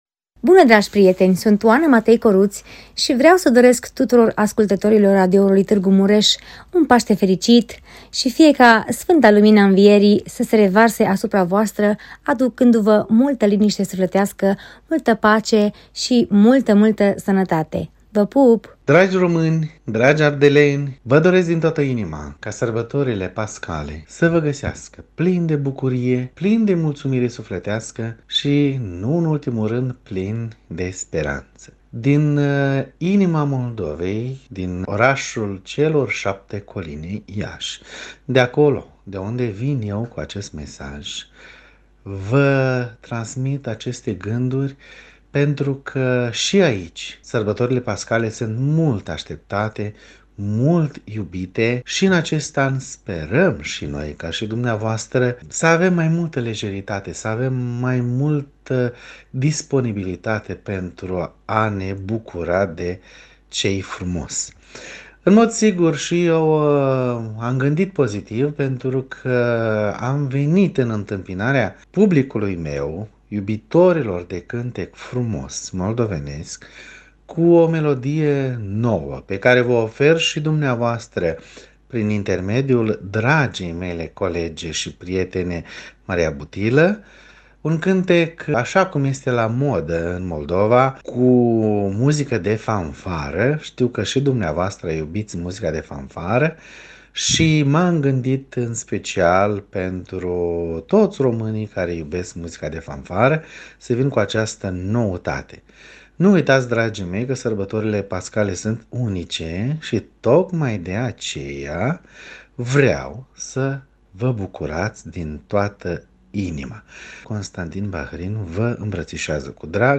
mesaje-de-urare-pasti-artisti-muzica-populara-1.mp3